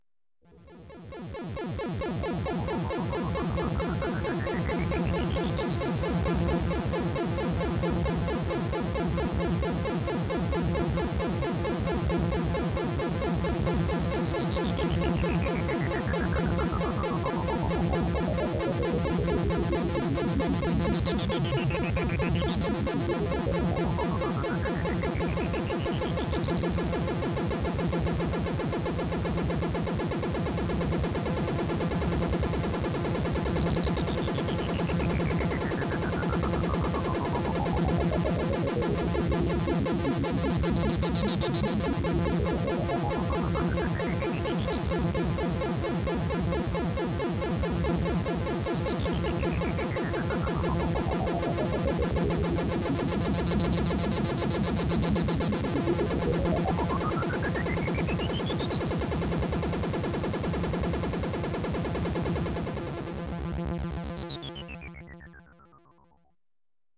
Computer Video Game - Fighting Sound
ambience